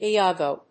音節I・a・go 発音記号・読み方
/iάːgoʊ(米国英語), iάːgəʊ(英国英語)/